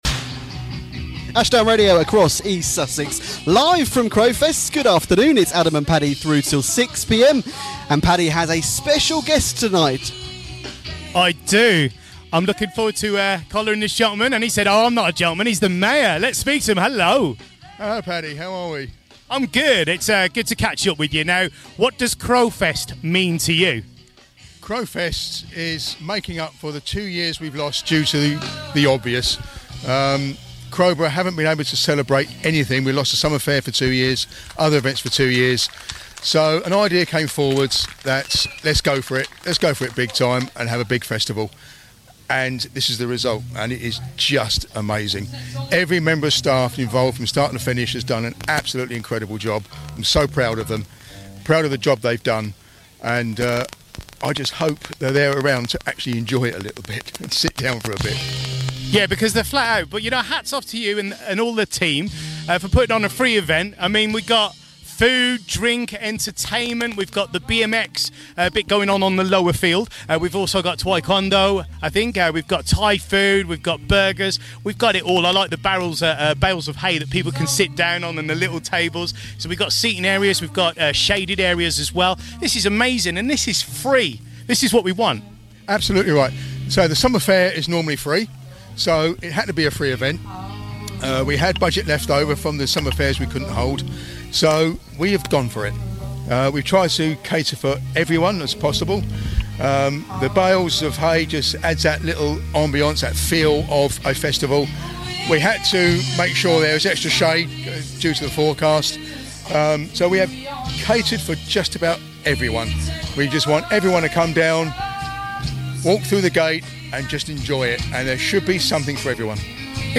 We were at Crowfest yesterday